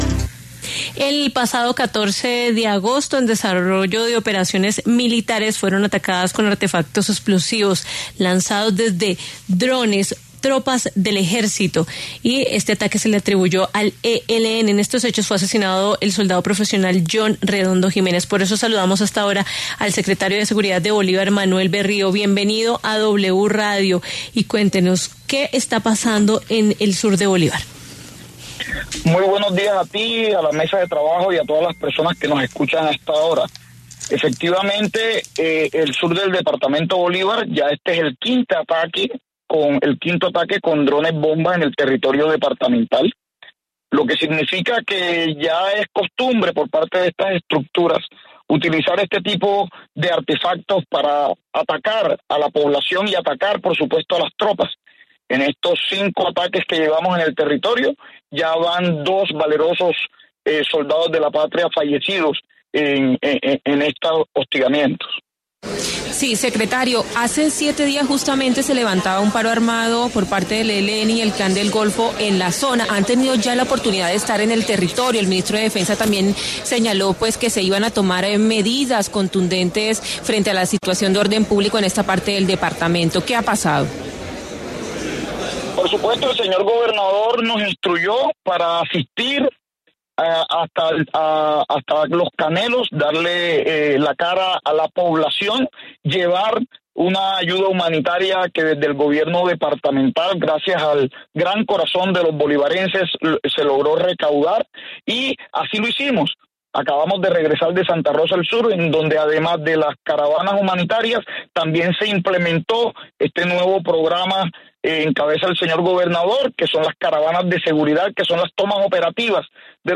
Manuel Berrio, secretario de Seguridad, habló de los recientes ataques con drones en el sur de Bolívar.